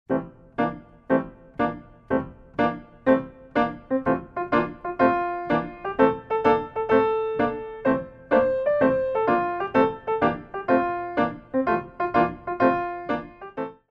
By Pianist & Ballet Accompanist
Piano selections include:
Tendu